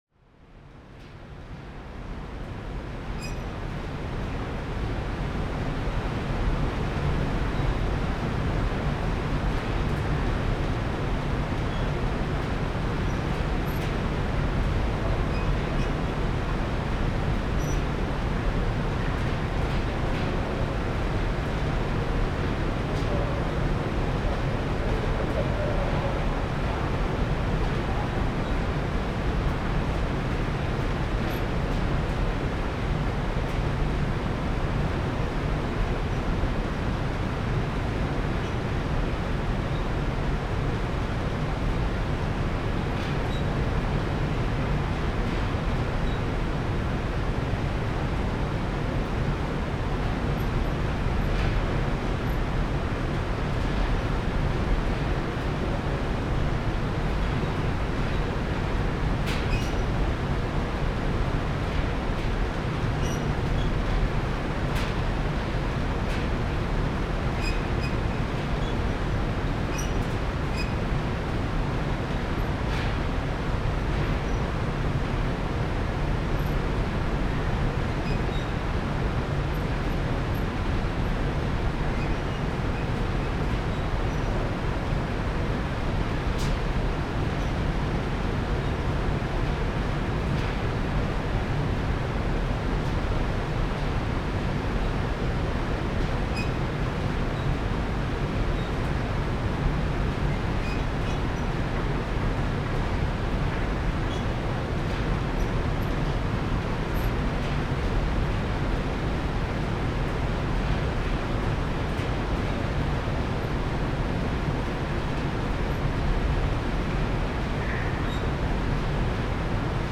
AMB_Scene04_Ambience_R.ogg